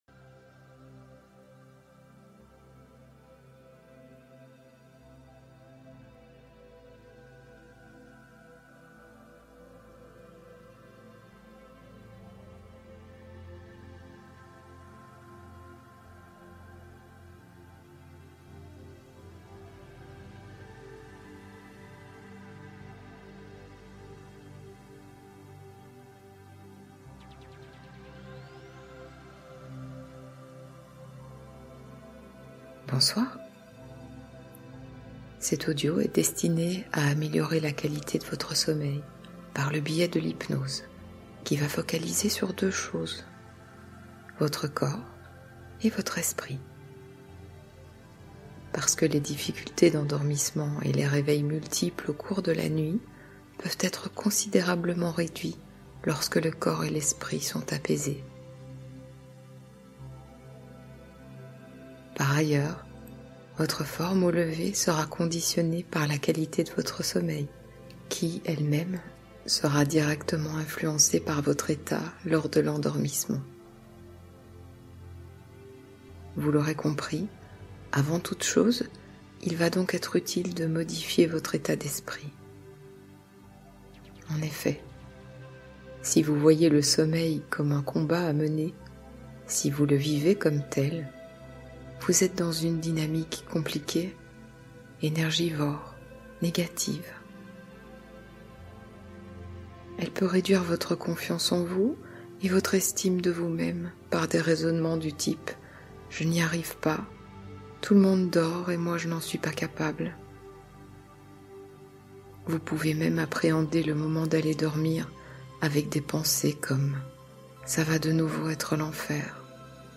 Sommeil paisible : hypnose lumineuse